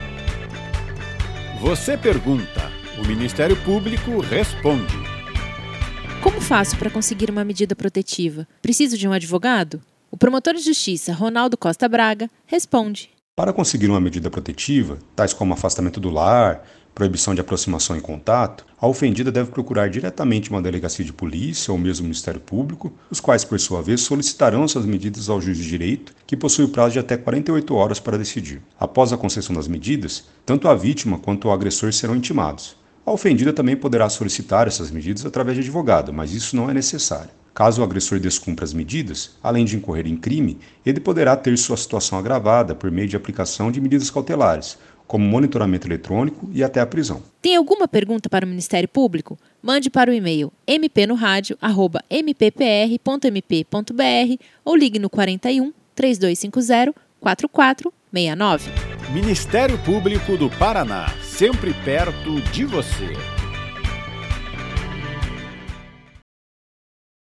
As dúvidas são respondidas pelo promotor de Justiça Ronaldo Costa Braga, do Ministério Público do Paraná.